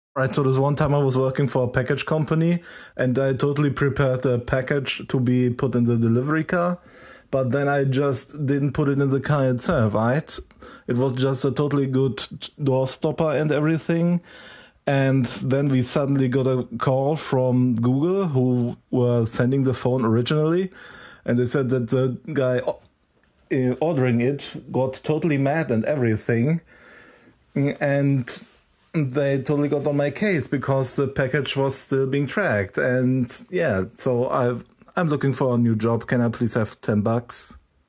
Quick mictest of my new phone, in which I am making a terrible attempt at apeing